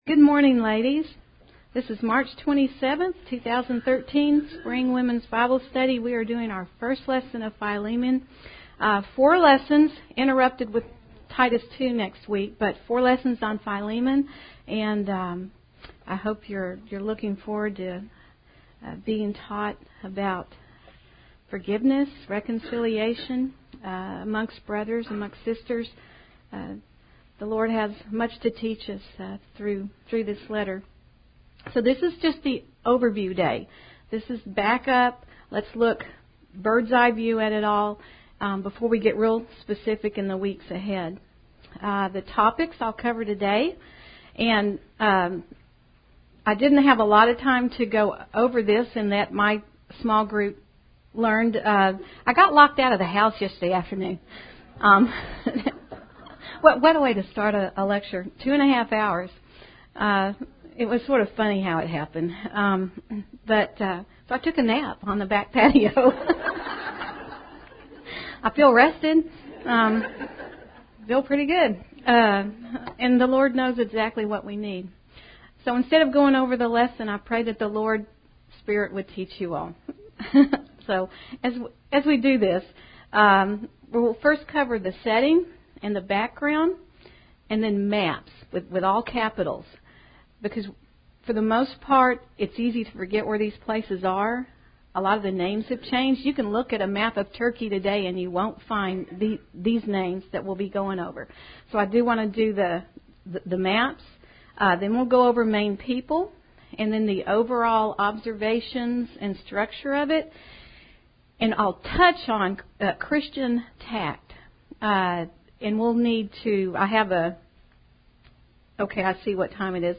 Women Women - Bible Study - Philemon Audio Series List Next ▶ Current 1.